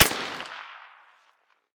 heav_crack_07.ogg